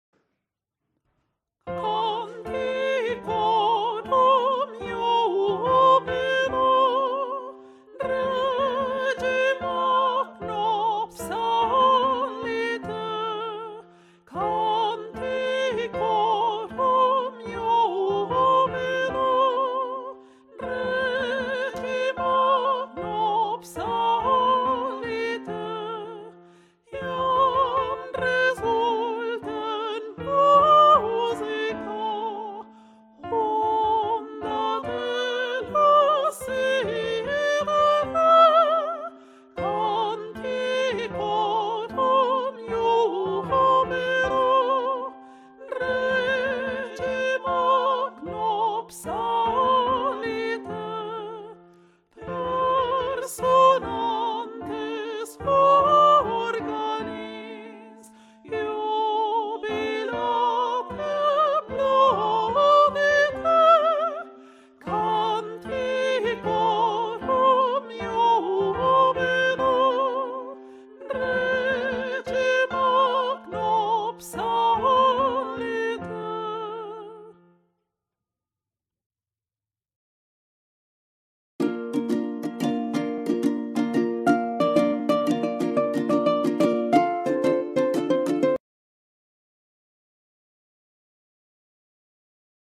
mp3 versions chantées
Tenor
Tenor Rehearsal F Major Bpm 77